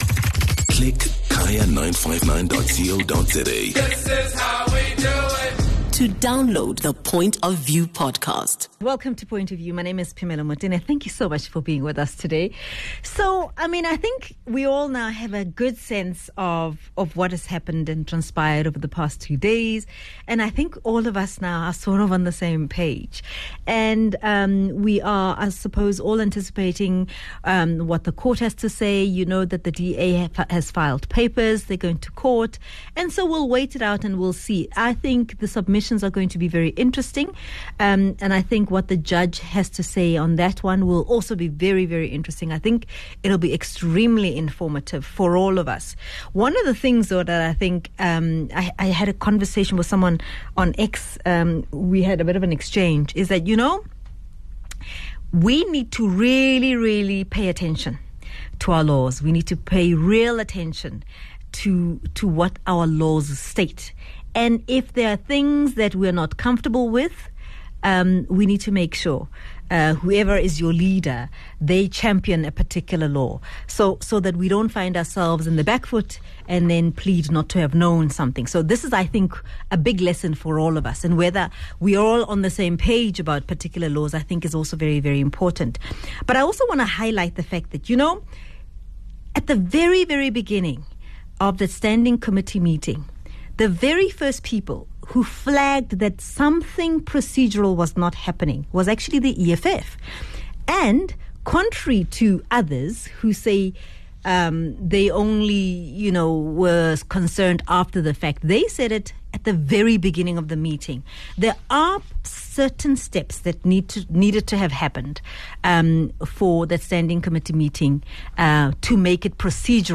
speaks to EFF member of parliament, Sinawo Thambo and former chairperson of the Standing Committee on Public Accounts, Themba Godi.